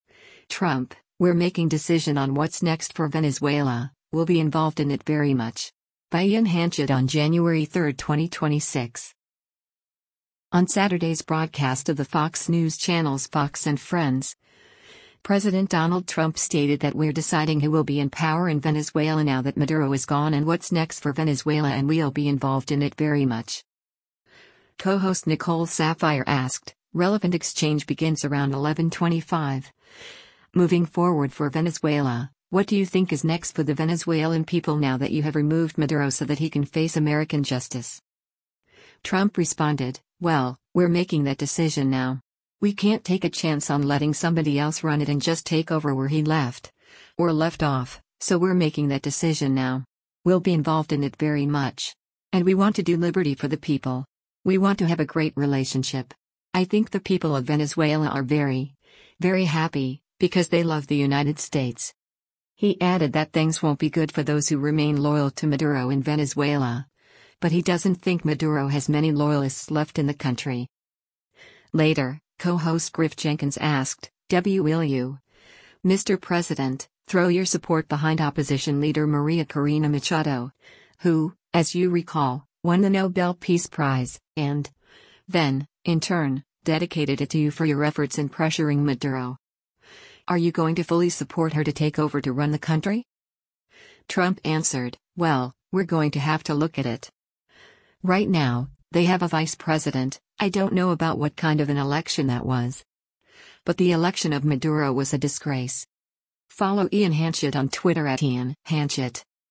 On Saturday’s broadcast of the Fox News Channel’s “Fox & Friends,” President Donald Trump stated that we’re deciding who will be in power in Venezuela now that Maduro is gone and what’s next for Venezuela and “We’ll be involved in it very much.”